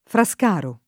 [ fra S k # ro ]